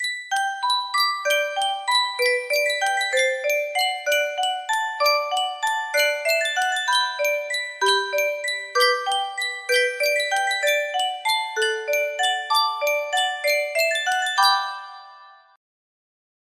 Yunsheng Custom Tune Music Box - Cuckoo Waltz music box melody
Full range 60